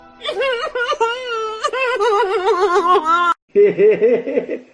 Reactions
Goofy Ahh Sobbing Laugh Meme